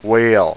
WHALE.WAV